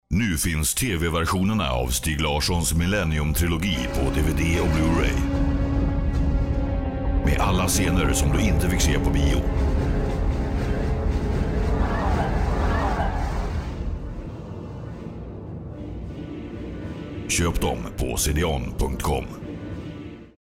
Movie Trailer